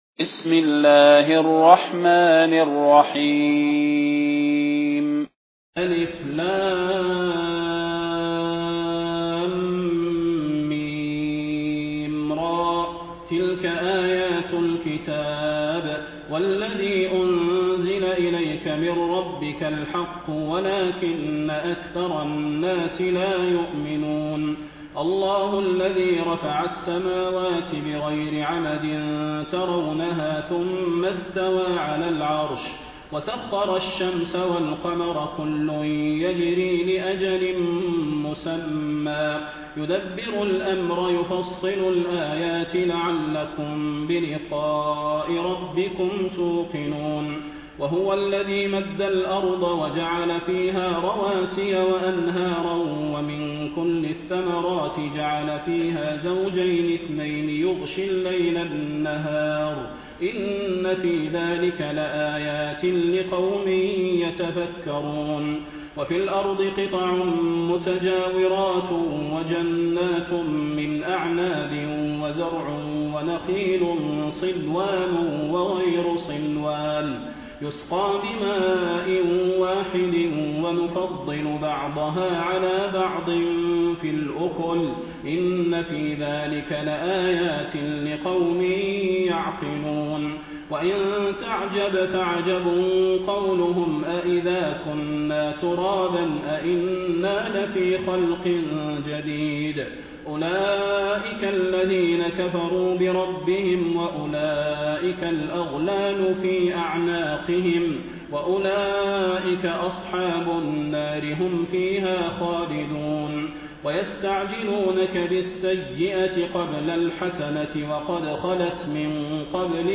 المكان: المسجد النبوي الشيخ: فضيلة الشيخ د. صلاح بن محمد البدير فضيلة الشيخ د. صلاح بن محمد البدير الرعد